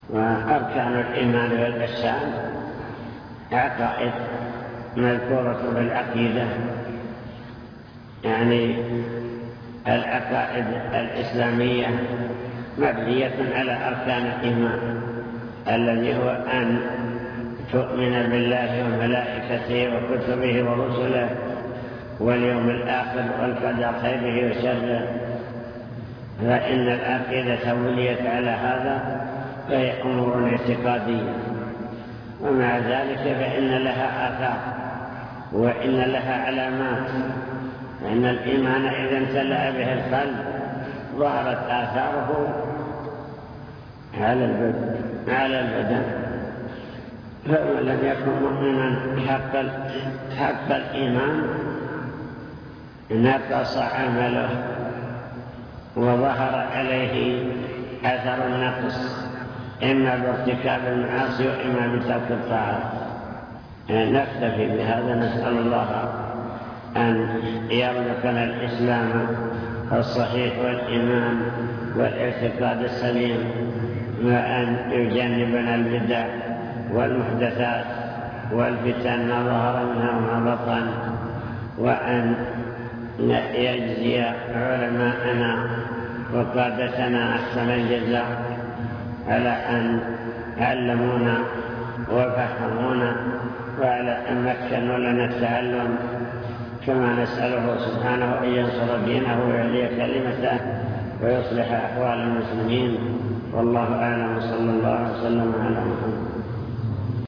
المكتبة الصوتية  تسجيلات - محاضرات ودروس  الإسلام والإيمان والإحسان